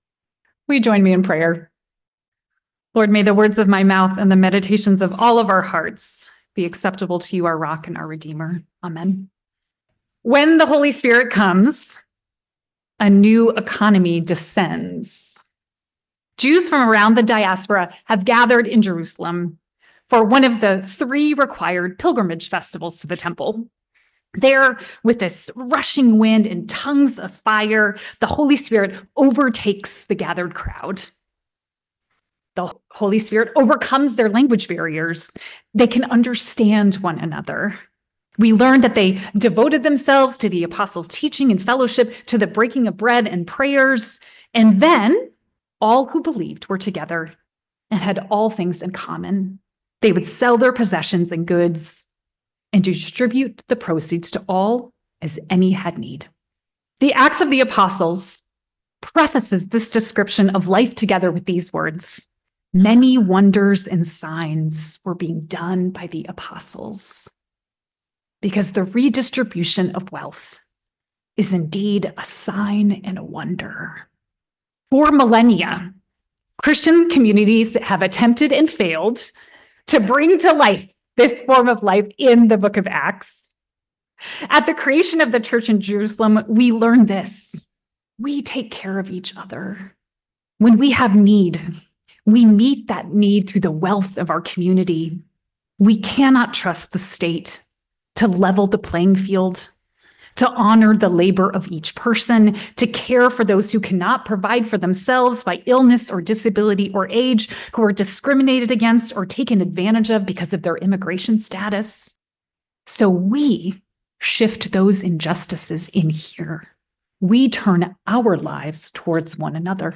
Religion Christianity